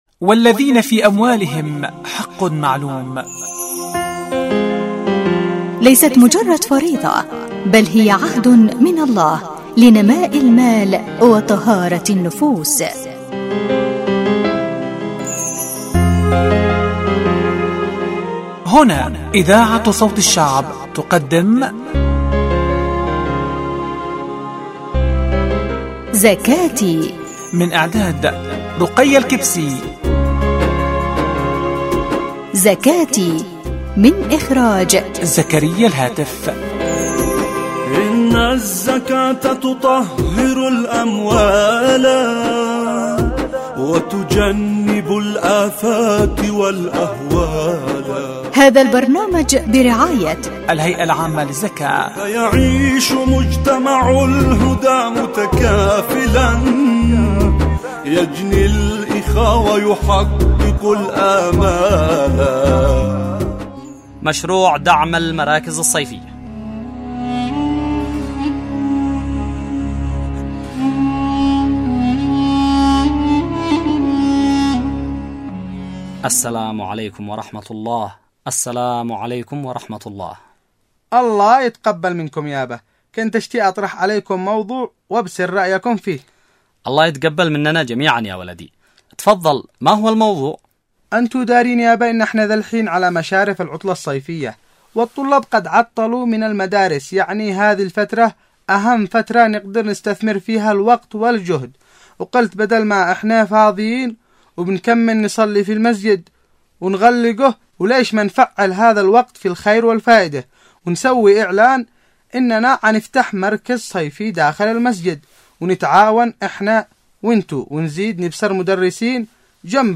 البرامج الحوارية زكاتي زكاتي ح15